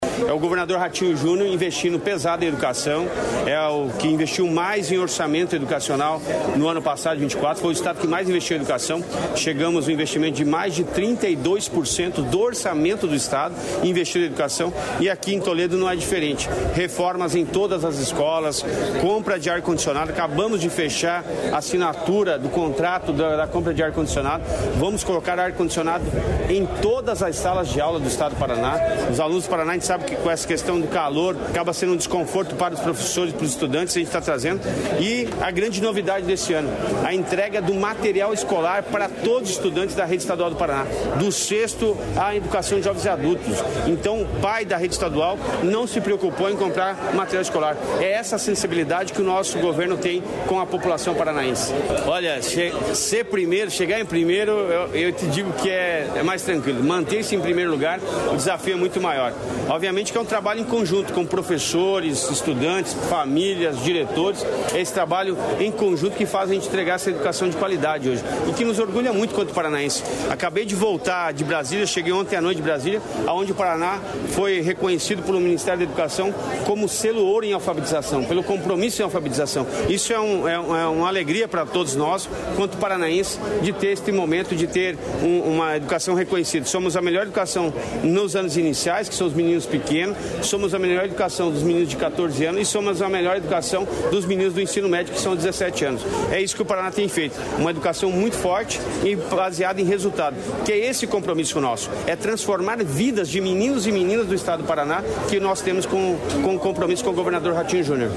Sonora do secretário da Educação, Roni Miranda, sobre a inauguração de colégio em Toledo